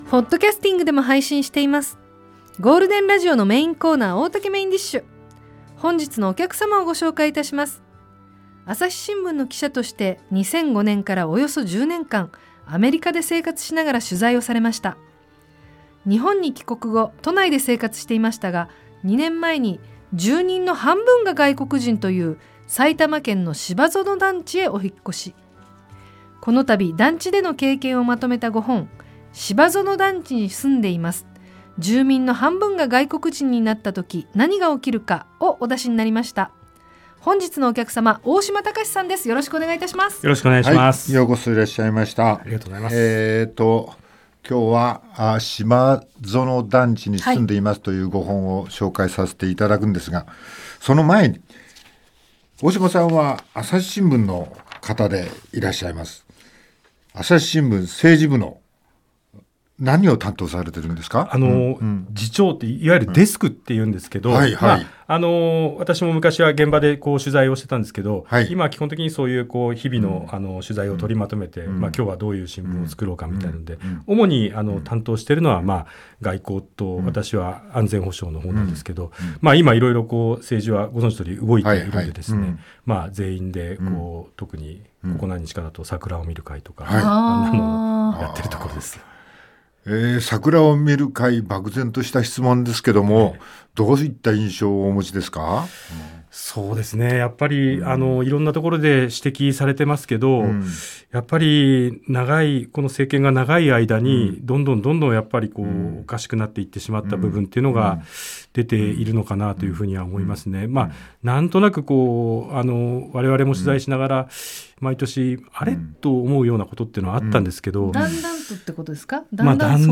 番組のメインを飾るゲストが登場！ 大竹まこと＆各パートナーがお客様のトークを料理します。